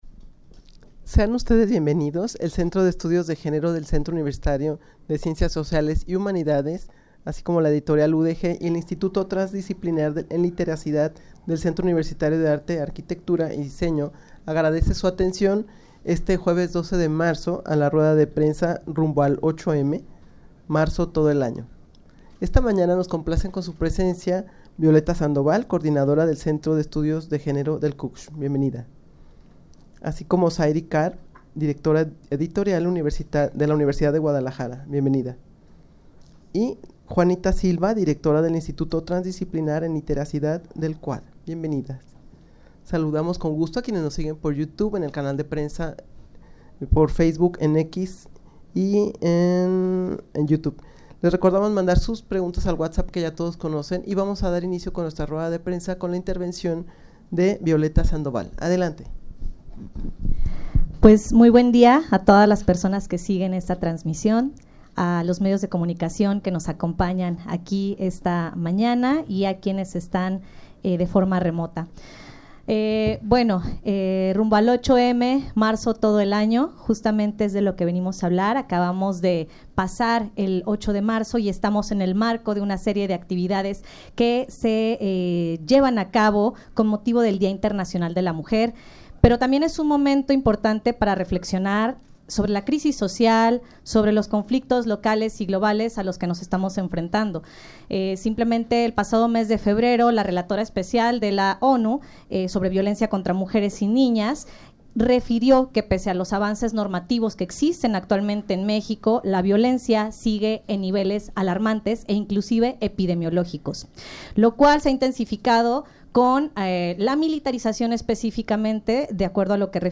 Audio de la Rueda de Prensa
rueda-de-prensa-rumbo-al-8m.-marzo-todo-el-ano.mp3